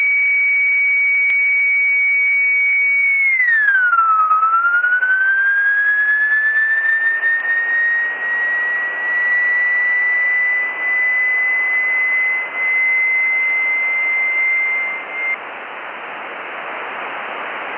RF Doppler measurement
If you want to listen to the raw data itself, here you go: data.wav. Yes, that's the audio straight out of the radio receiver, received in upper side band mode. That's the RF Doppler effect right there, downconverted into audio.
There was plenty of quantization noise, and the occasional extra blip, but a second low-pass filter took care of those.